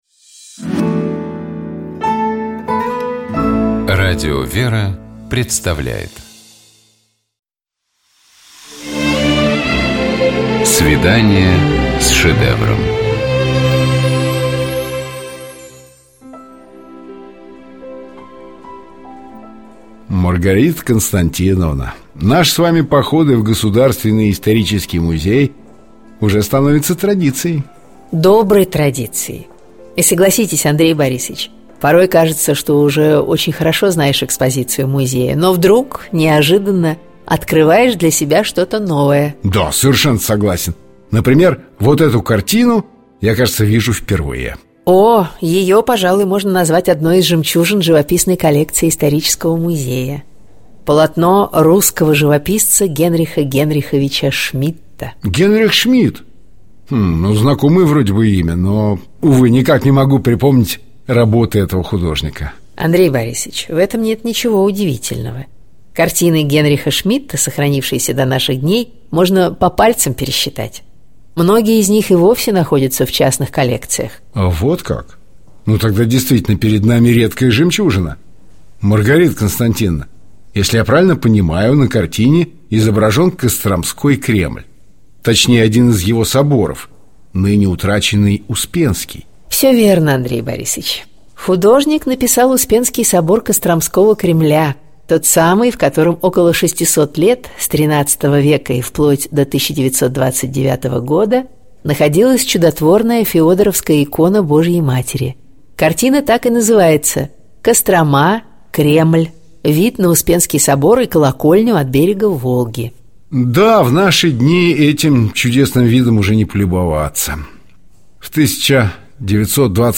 Коридор картиной галереи с посетителями.